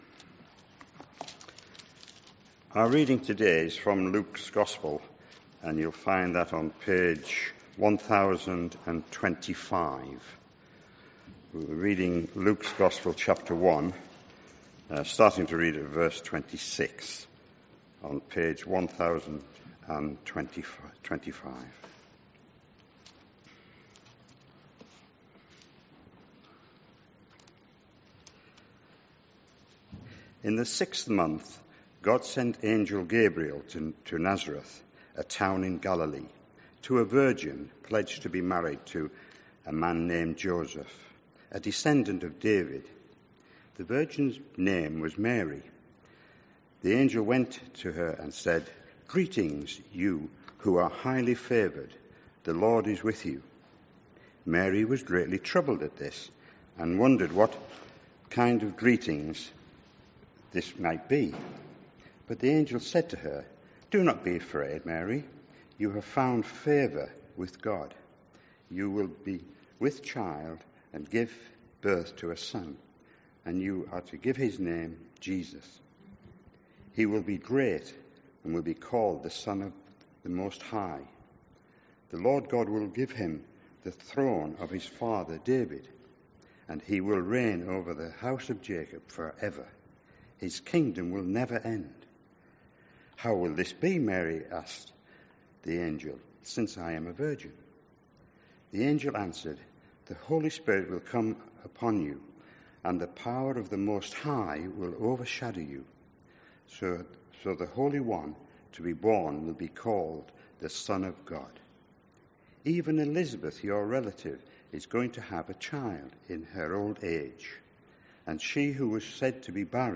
This sermon, for the first Sunday in advent covers the foretelling of Jesus’ birth and Mary’s song from Luke 1.